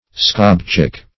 Search Result for " squab-chick" : The Collaborative International Dictionary of English v.0.48: Squab-chick \Squab"-chick`\ (-ch[i^]k`), n. (Zool.)